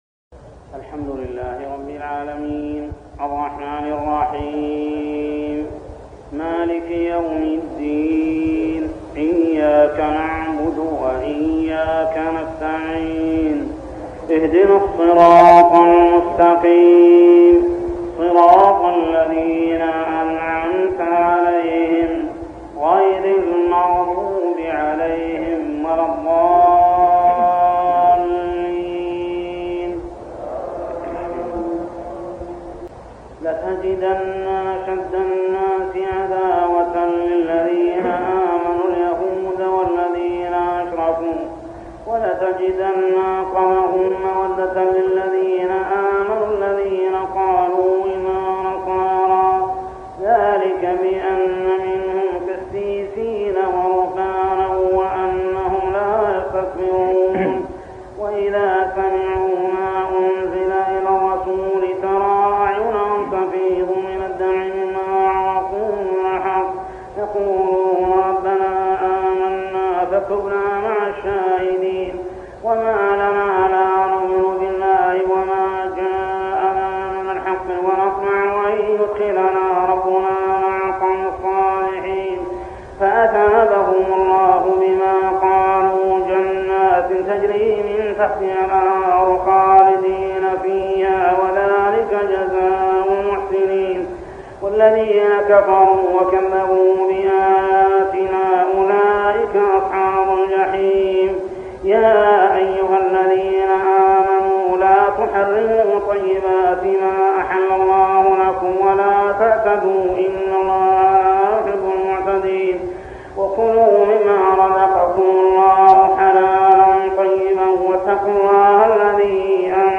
صلاة التراويح عام 1403هـ سورتي المائدة 82-120 و الأنعام 1-27 | Tarawih prayer Surah Al-Ma'idah and Al-An'am > تراويح الحرم المكي عام 1403 🕋 > التراويح - تلاوات الحرمين